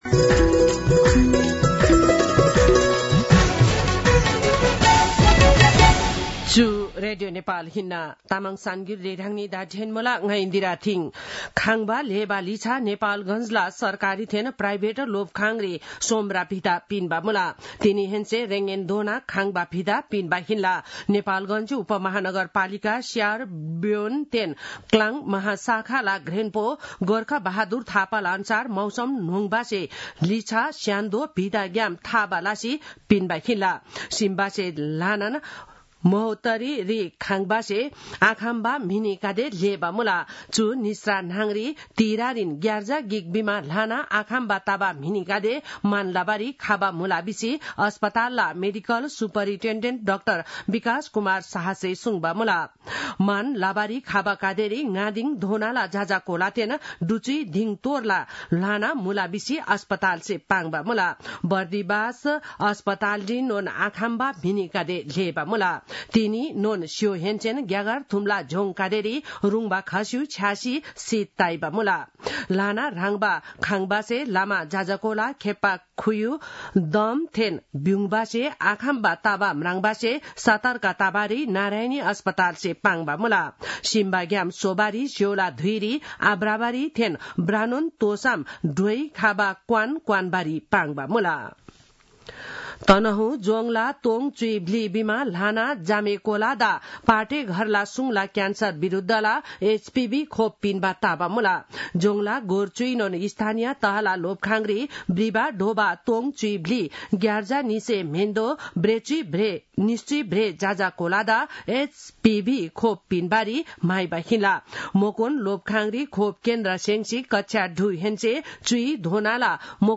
तामाङ भाषाको समाचार : १० माघ , २०८१